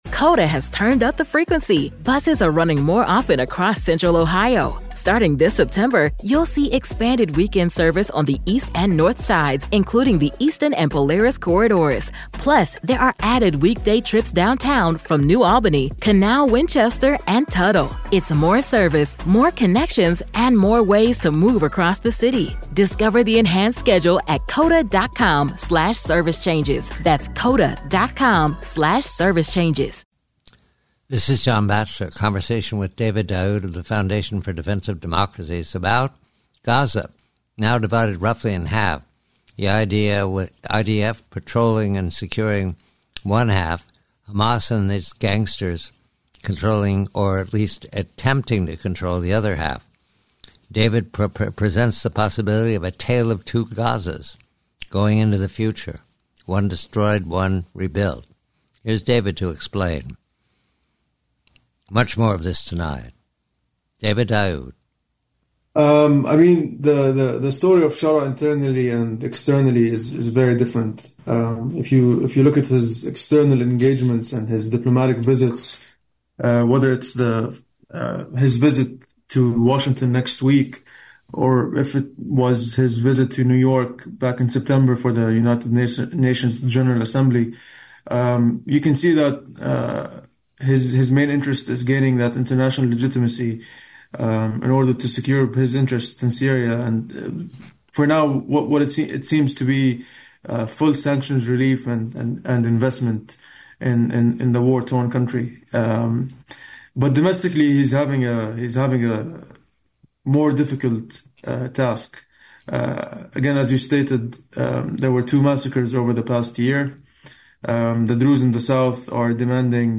speaks with John Batchelor about Gaza, now divided with the IDF patrolling one half and Hamas controlling the other.